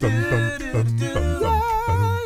ACCAPELLA 5B.wav